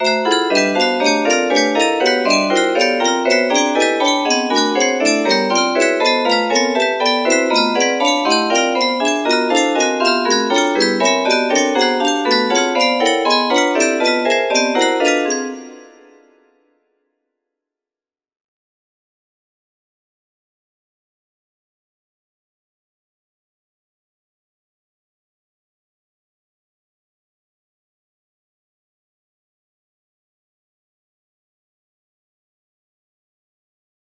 P16 music box melody